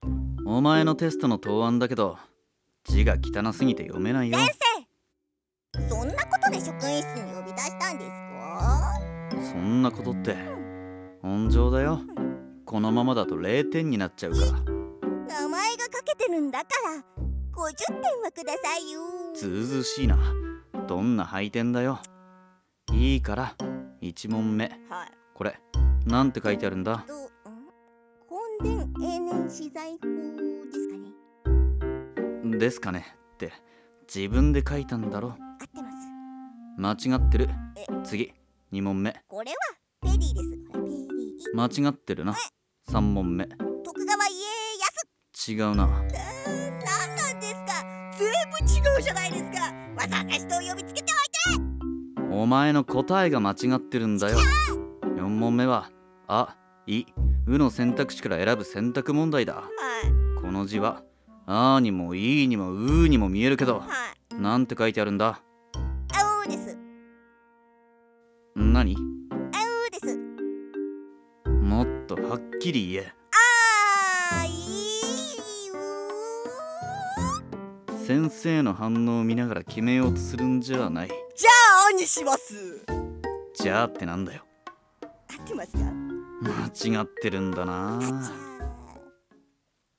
【ギャグ声劇】テストの字が汚い（台本）